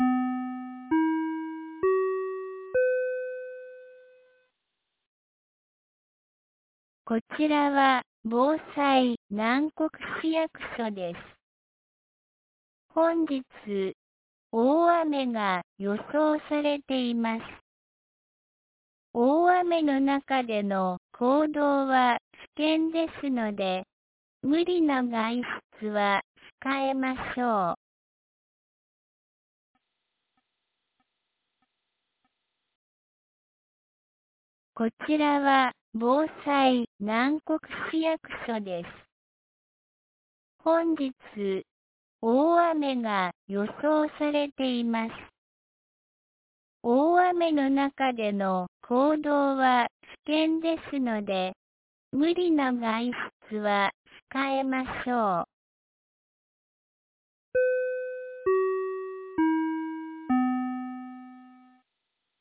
2025年07月17日 13時31分に、南国市より放送がありました。